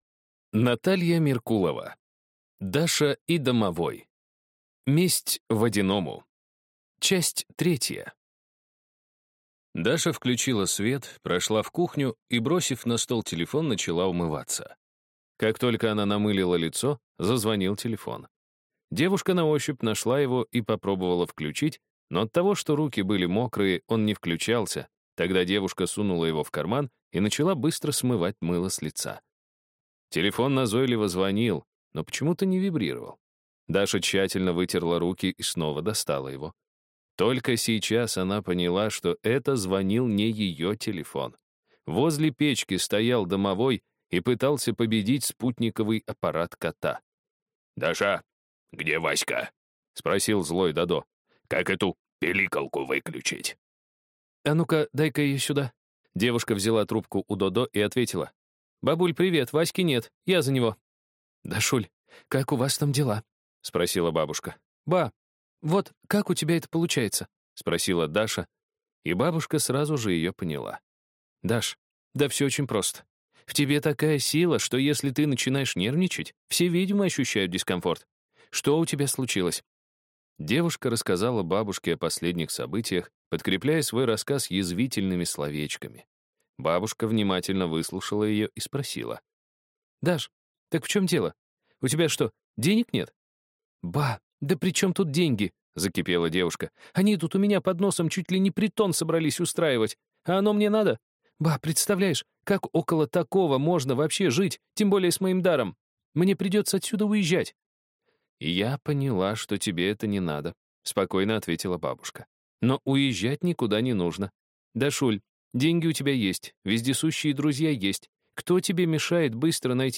Аудиокнига Даша и домовой. Месть водяному | Библиотека аудиокниг